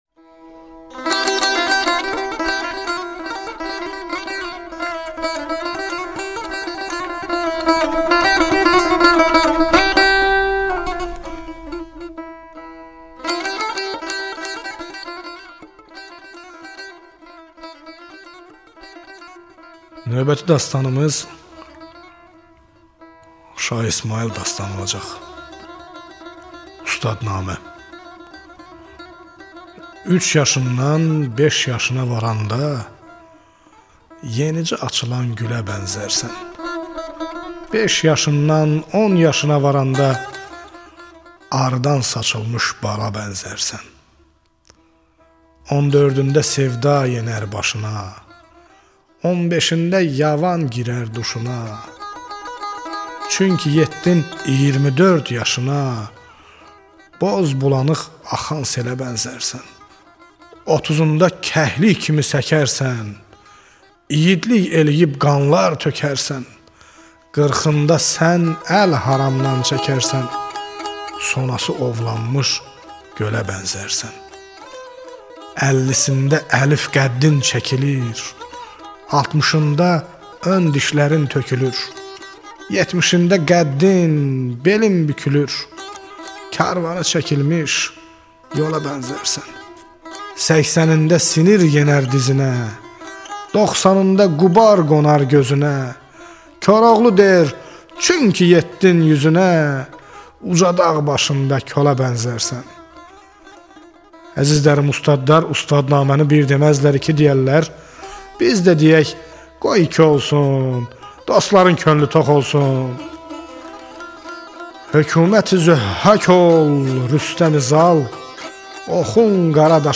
Azeri dastan